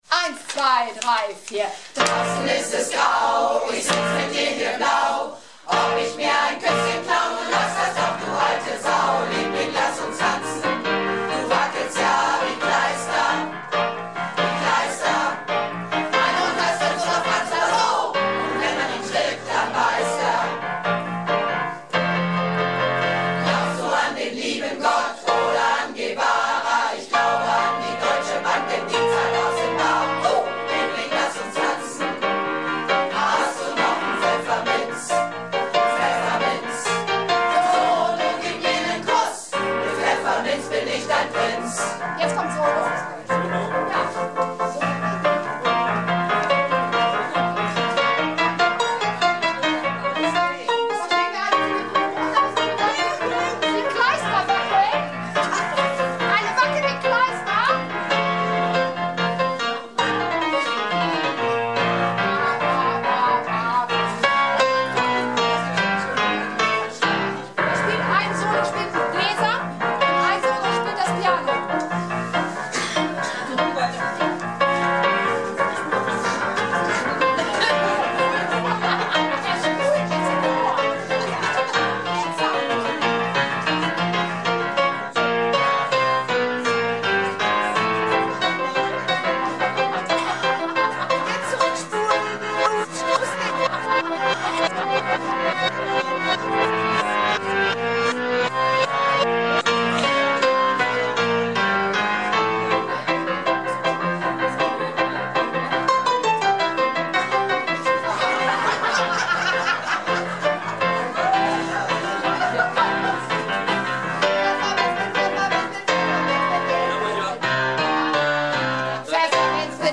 Piano Extravaganza